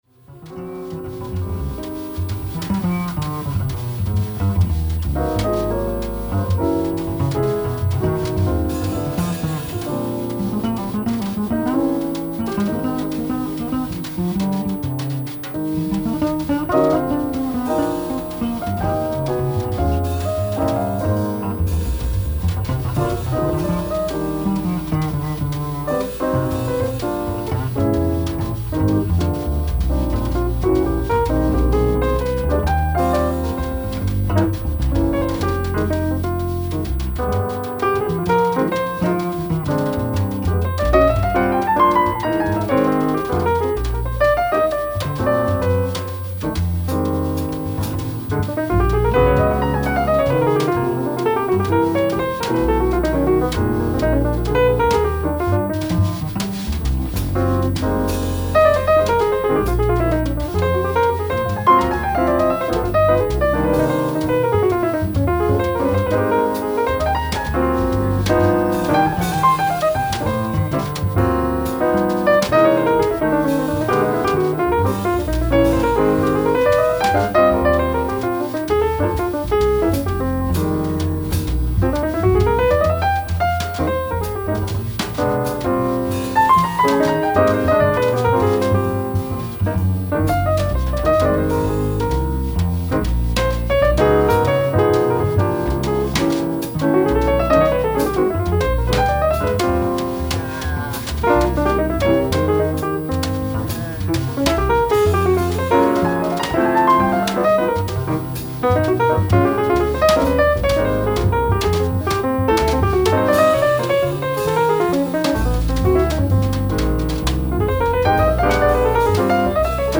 ライブ・アット・サーカスビル、コペンハーゲン、デンマーク 07/01/1999
※試聴用に実際より音質を落としています。